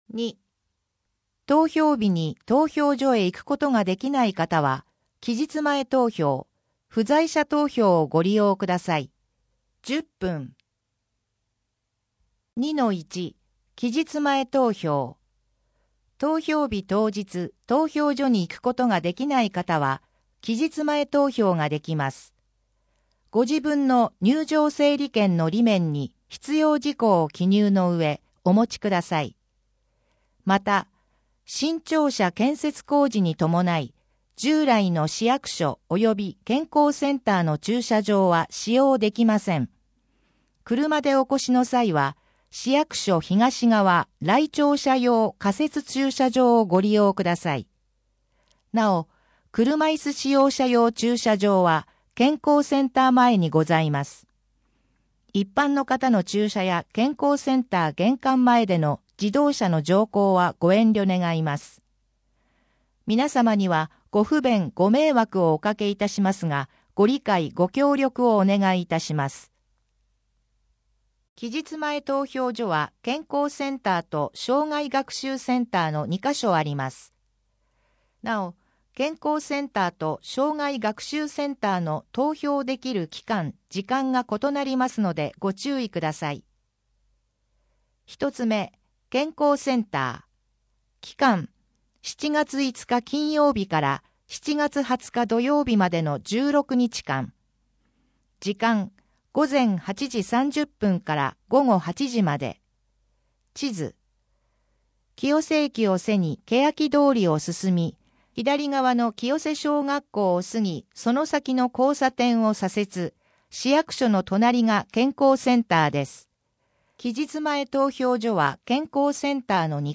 声の広報は清瀬市公共刊行物音訳機関が制作しています。